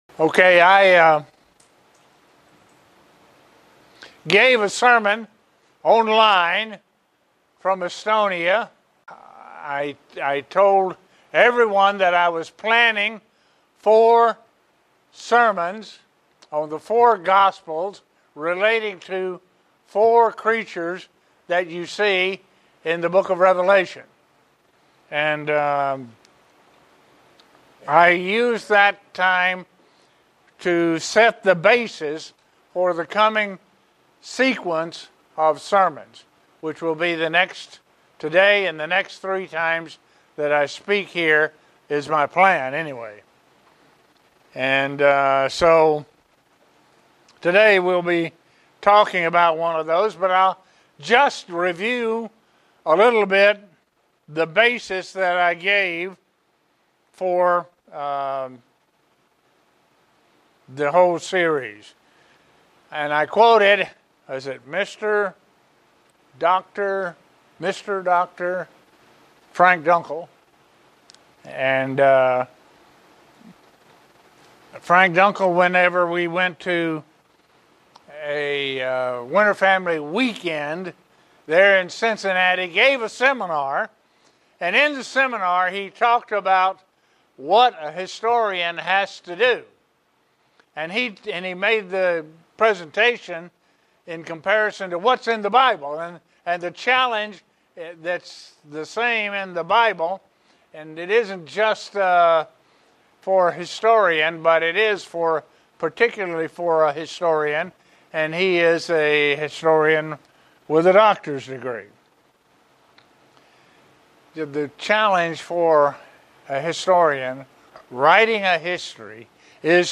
Print To explain what the Face of the Lion represents in the New Testamet. sermon Studying the bible?